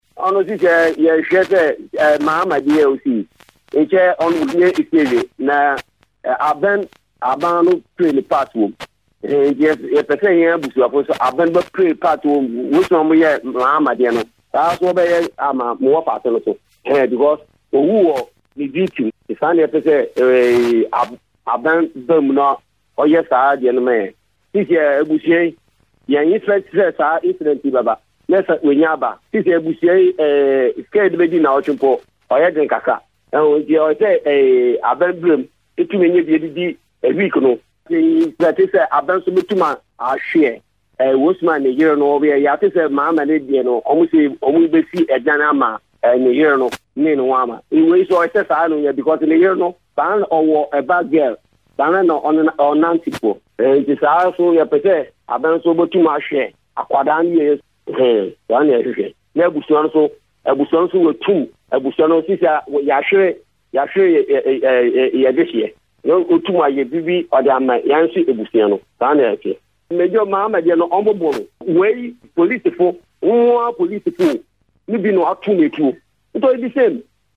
an Uncle of the deceased policeman said in an interview